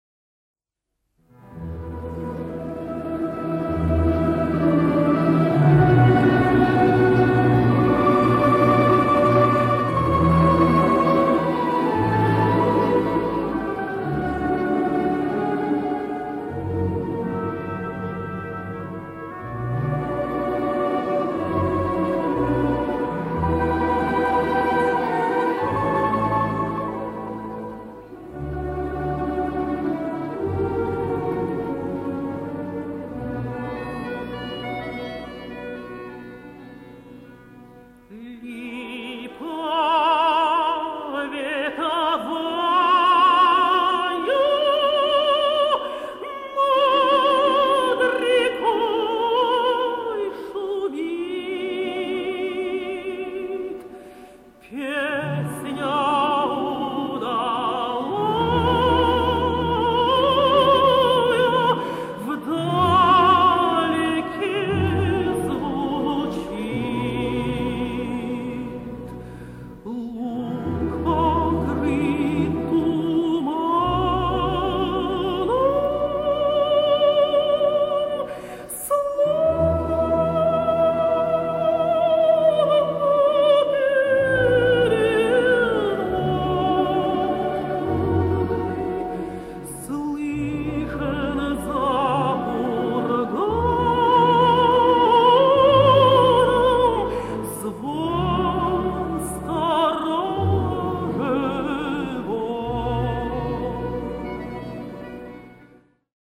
Народная песня «Липа вековая».
Валентина Левко и ОРНИ имени Осипова.